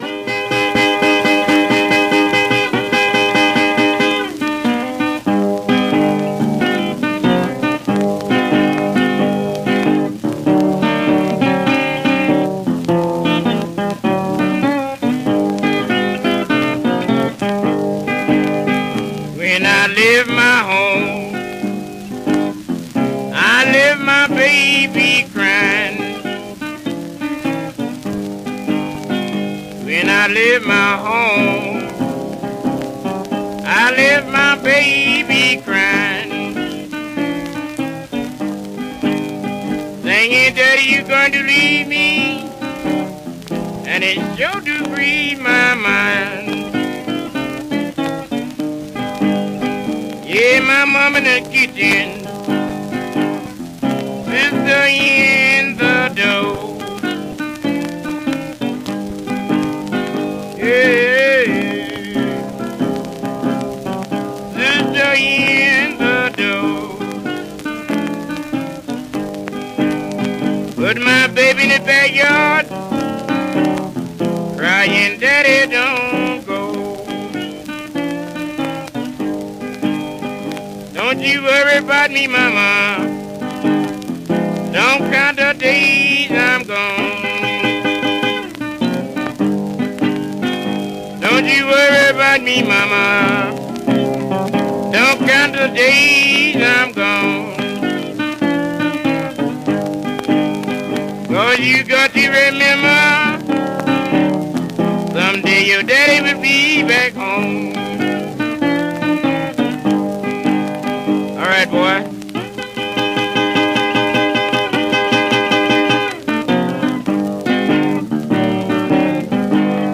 guitar